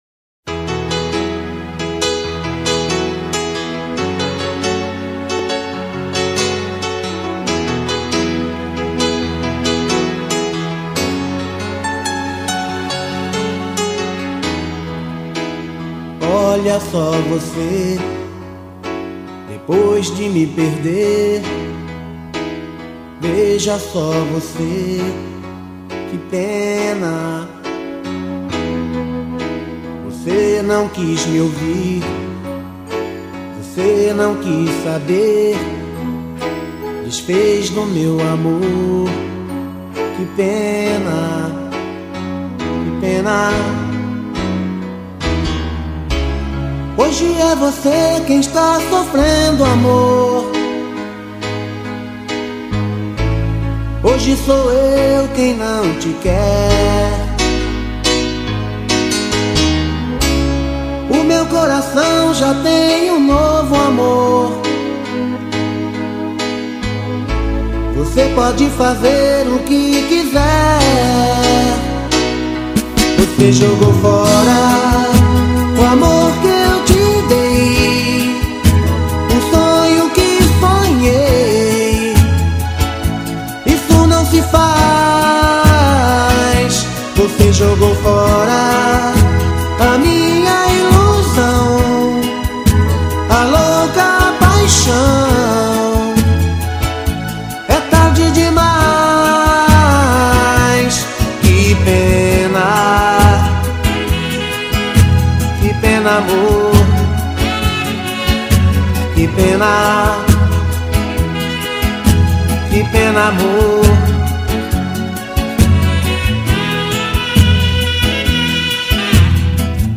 2024-03-03 22:00:10 Gênero: Samba Views